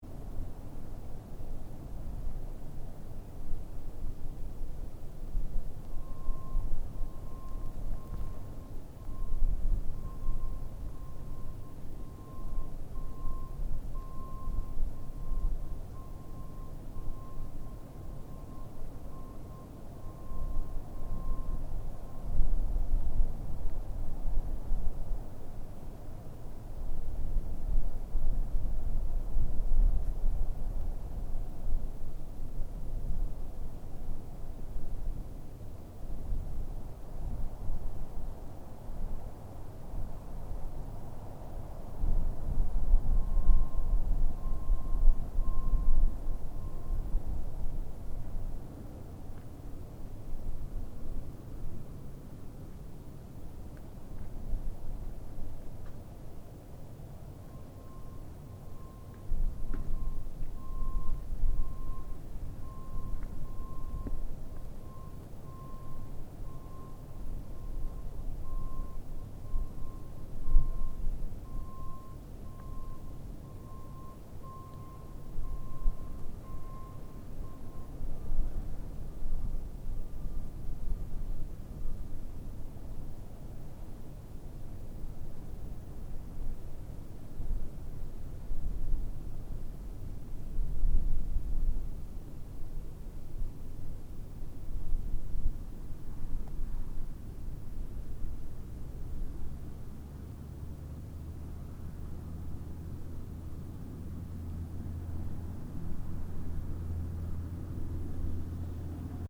Soundscape: Paranal exterior during the day
On a non-windy day, the ambient sound outside the observatory is very quiet, only a few cars moving to and from the telescopes platform at Paranal’ s summit and reverse parking —which is compulsory for safety reasons— can be heard.
ss-paranal-exterior-day_stereo.mp3